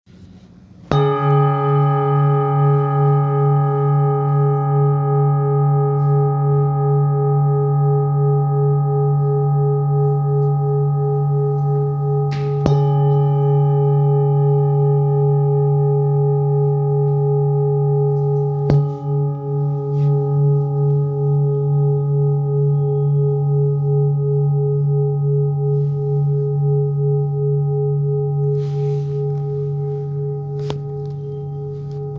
Singing Bowl, Buddhist Hand Beaten, with Fine Etching Carving Medicine Buddha, Thangka Color Painted, Select Accessories
Material Seven Bronze Metal